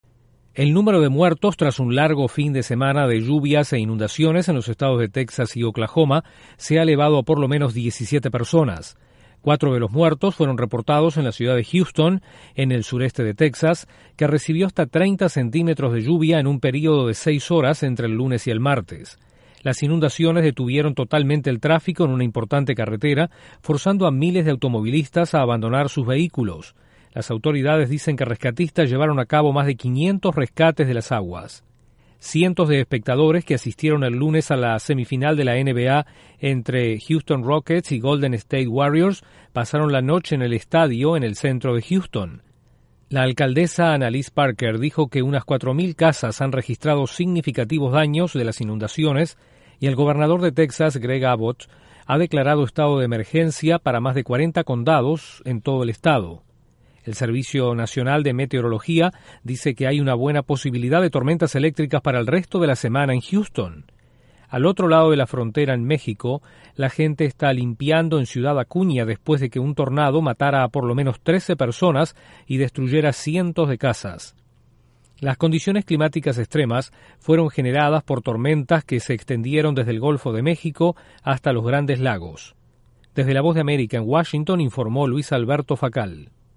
Aumenta el número de muertos tras las inundaciones en la zona sur central de Estados Unidos. Desde la Voz de América en Washington informa